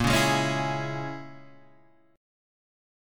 A#m7b5 chord